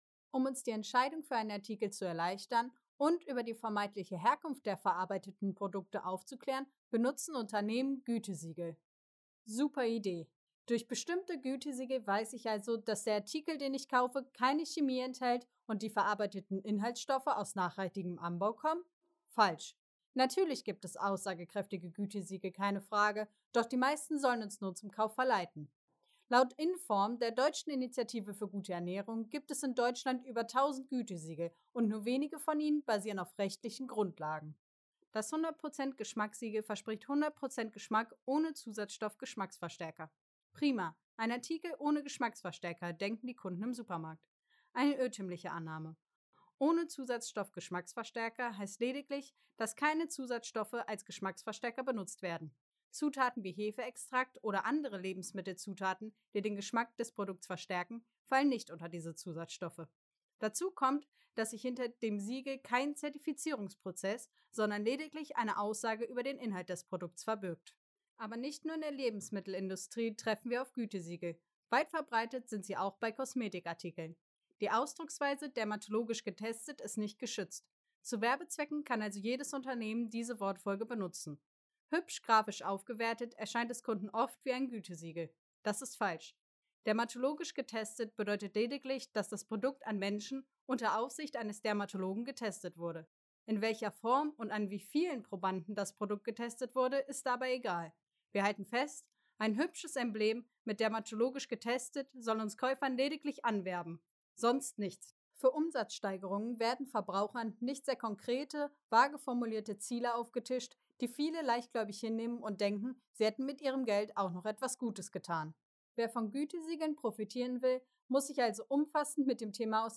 Aber wie zuverlässig sind die Angaben dieser kleinen Embleme und können wir ihnen wirklich vertrauen? Ein Kommentar